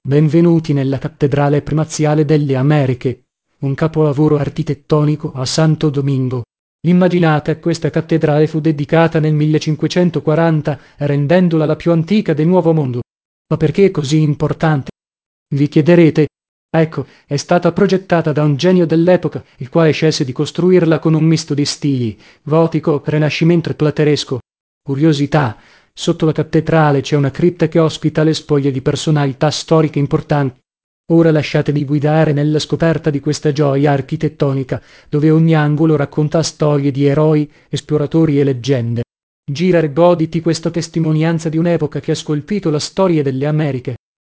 karibeo_api / tts / cache / b4608e0b75e08a2ab13f52f8e8ea570b.wav